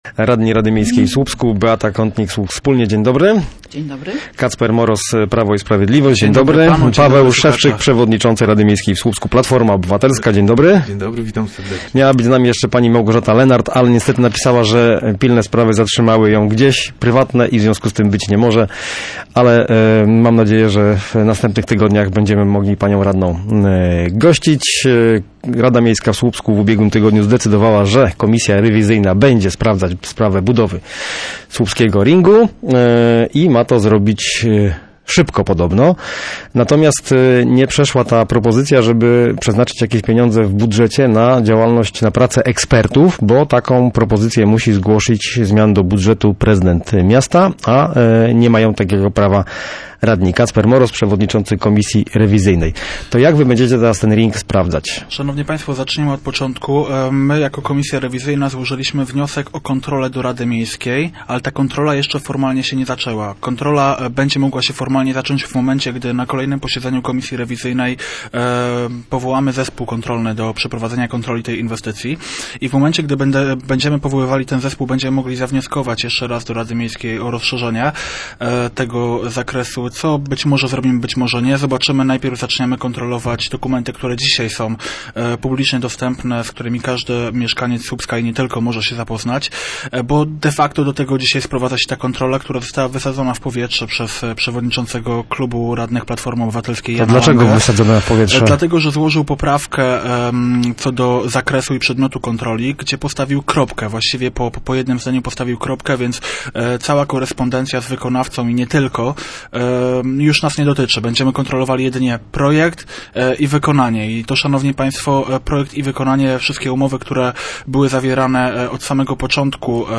Mamy nadzieję, że uda się wyjaśnić, dlaczego ta inwestycja napotkała na takie problemy w realizacji – dodają goście miejskiego programu Radia Gdańsk Studio Słupsk 102 FM.
– Moim zdaniem niepotrzebnie zawężono zakres kontroli, ale zaczynamy pracę – zapowiada Kacper Moroz z PiS, przewodniczący komisji rewizyjnej rady miejskiej w Słupsku.